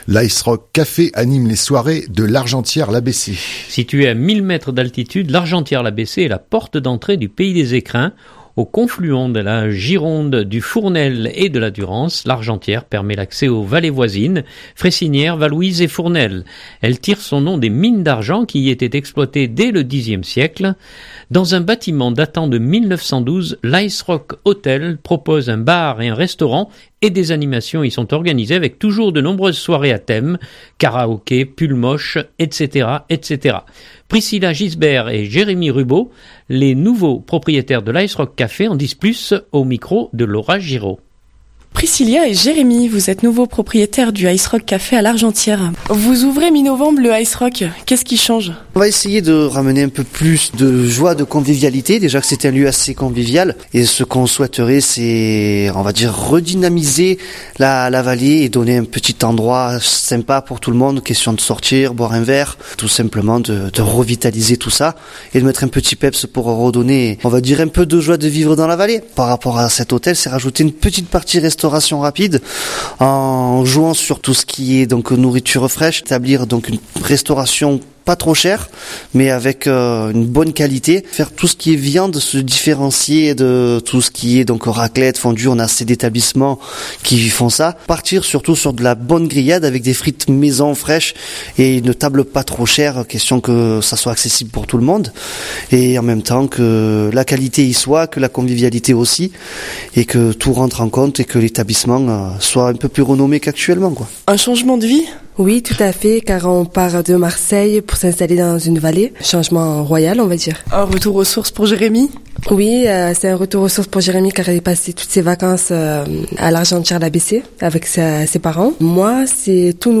reportage ice rock 2019-11-13.mp3 (2.67 Mo)